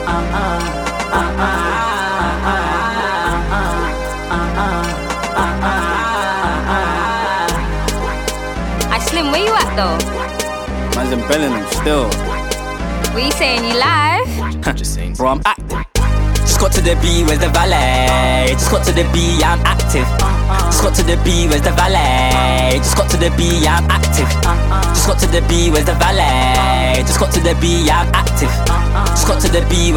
Underground Rap Hip-Hop Rap
Жанр: Хип-Хоп / Рэп